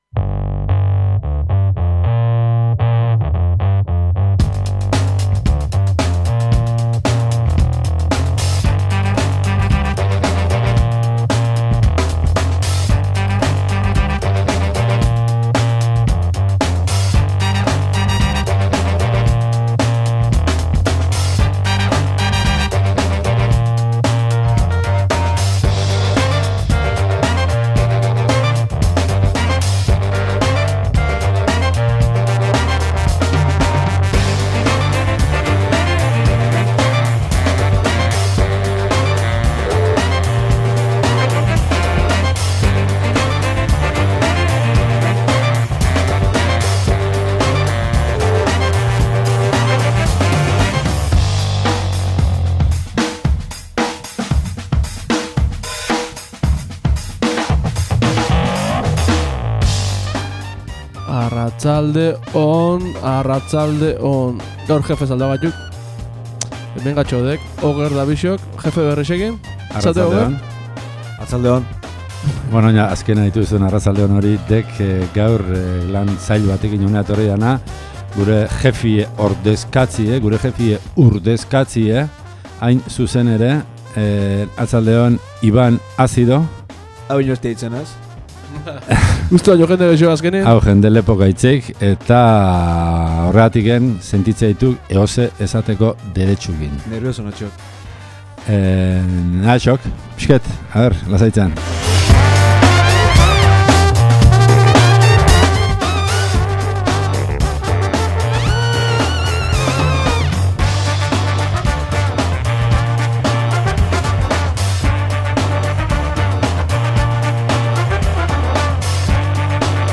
Eztabaida izan dugu gaur, EBeltza/Bizardunak afera de la ETA. Zer espero da jendeagandik? talde bategandik? zer da autentikoa izatea? eta abar eta abar….